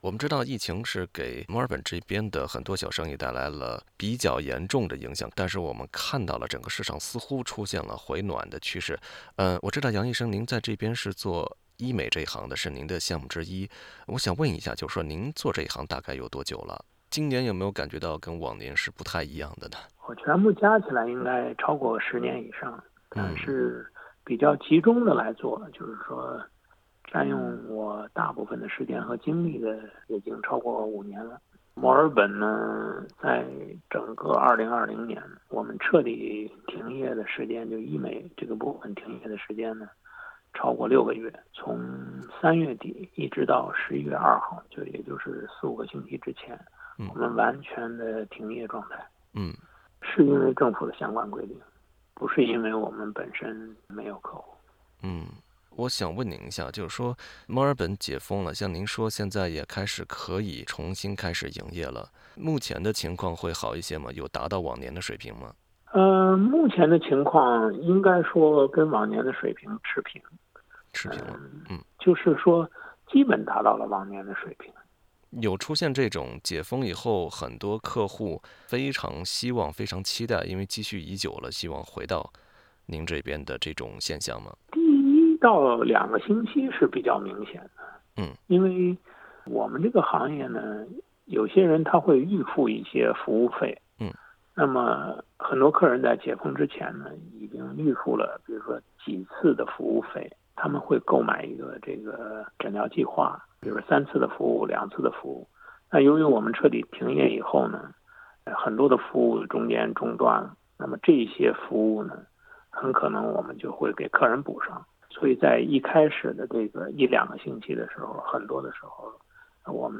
墨尔本的医美诊所曾因疫情的发酵而一度停止营业。疫情期间，澳洲严格的边境管制也影响了诊所的国际客源。（欢迎点击音频，收听完整采访。）